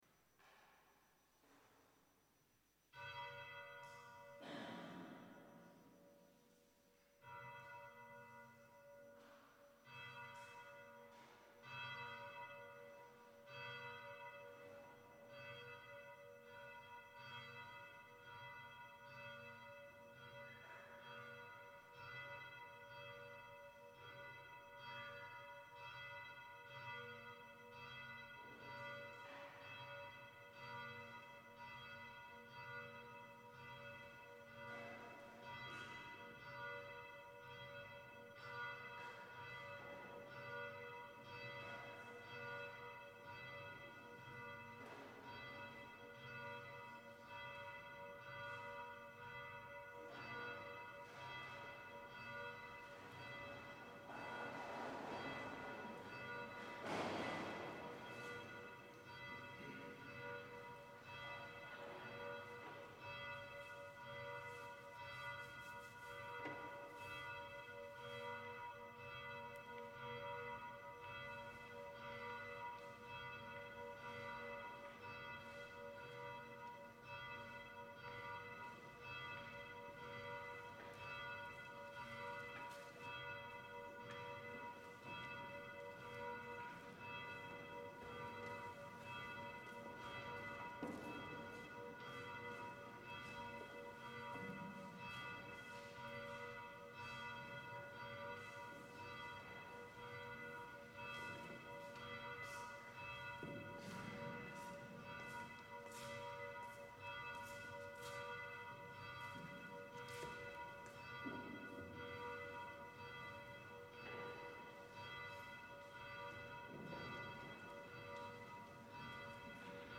Gregorian
Chanting Monk